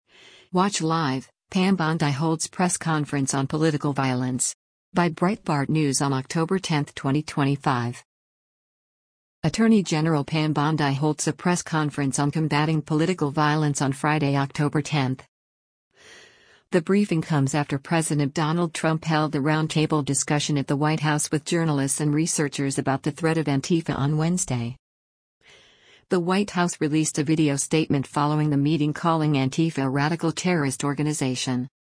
Attorney General Pam Bondi holds a press conference on combatting political violence on Friday, October 10.